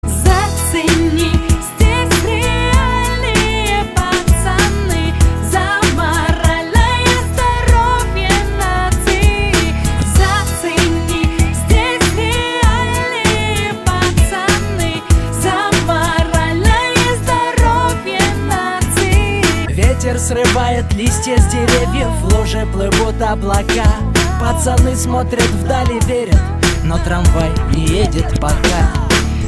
• Качество: 224, Stereo